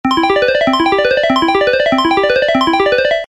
Звуки джекпота
Звук выбрали ставку на игровом автомате